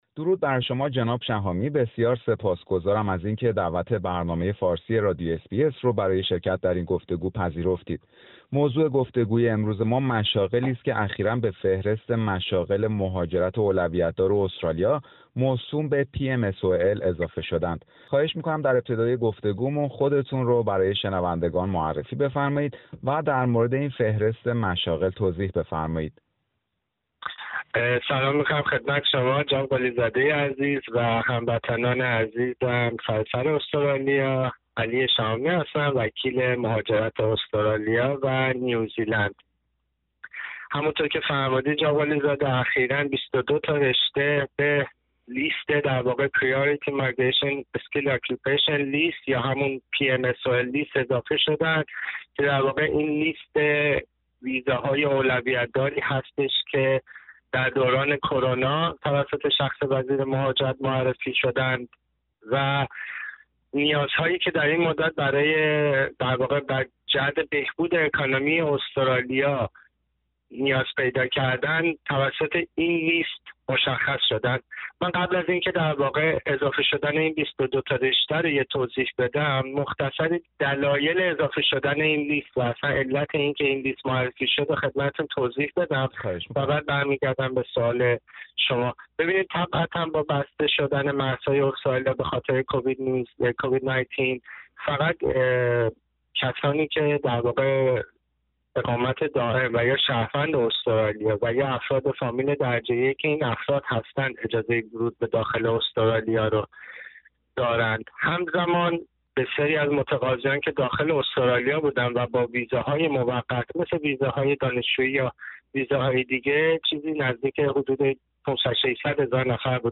گفتگویی درباره فهرست مشاغل مهارتی مهاجرت اولویت دار (PMSOL) و شغل های جدیدی که به آن اضافه شده است